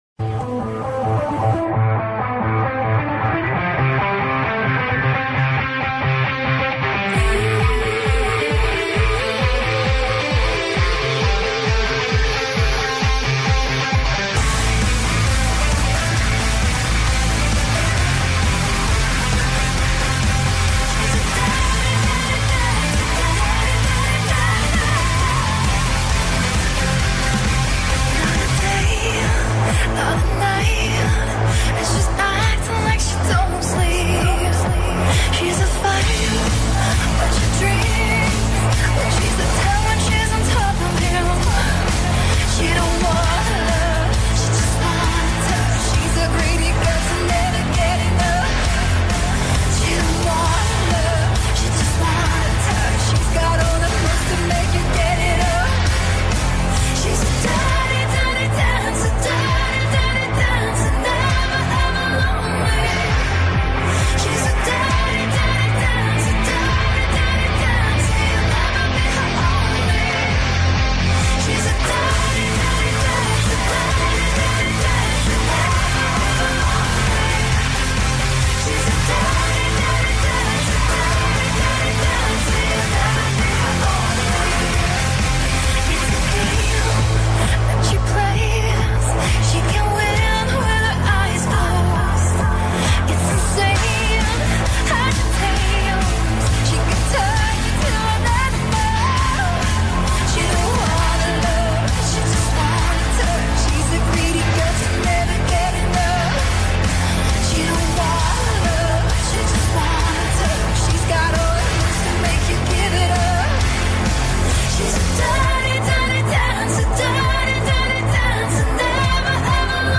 گروه معروف هلندی